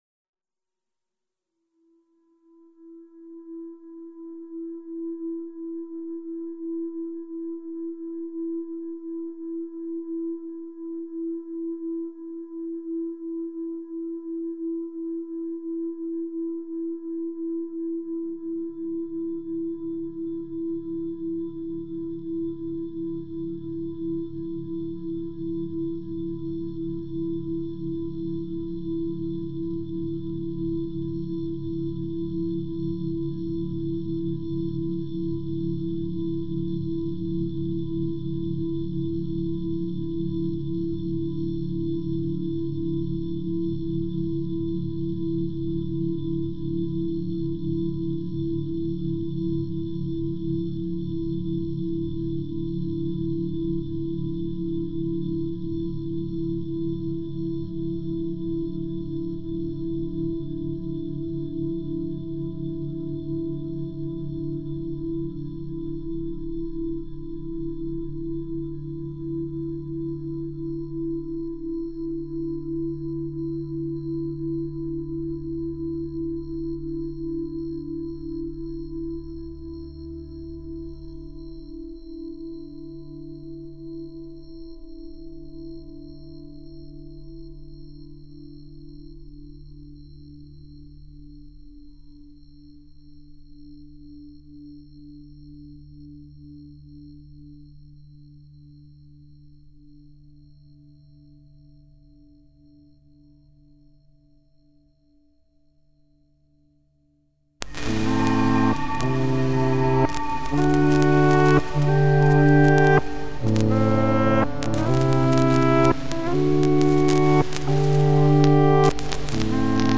File under: Experimental / Minimal Ambient / Drone Music